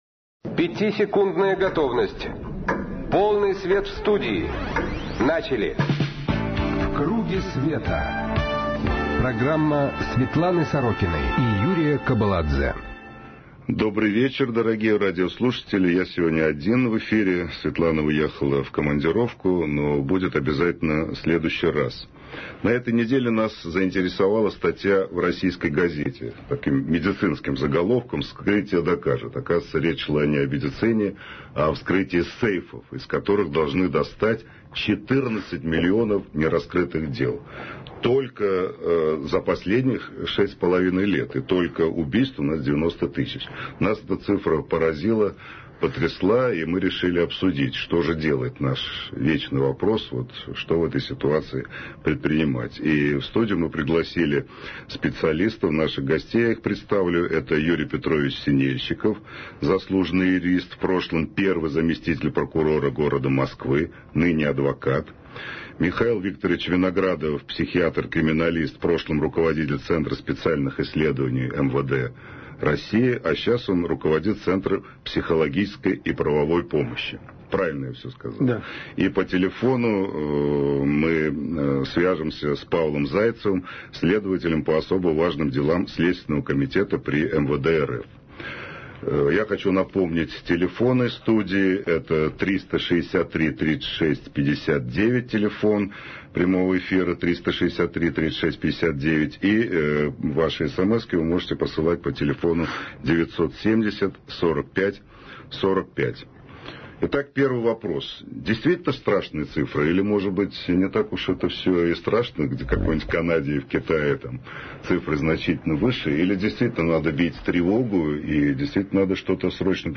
Светлана Сорокина: передачи, интервью, публикации